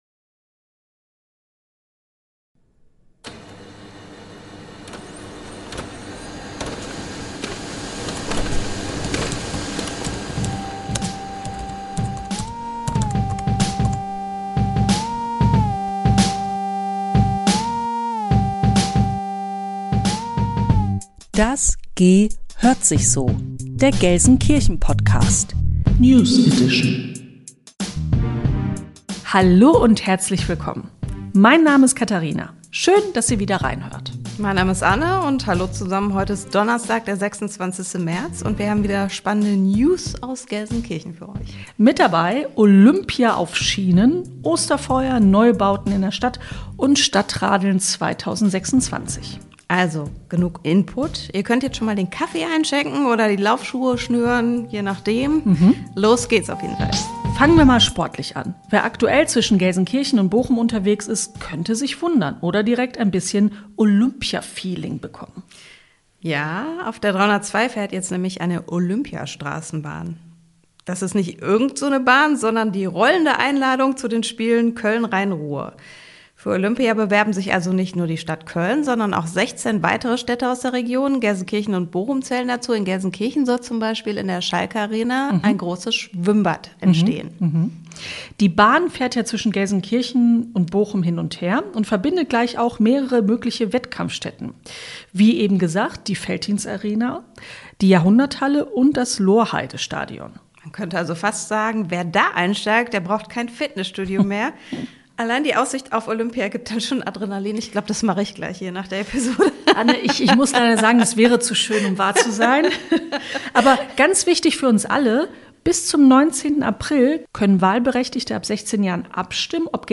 Nachrichten aus Deiner Stadt - direkt ins Ohr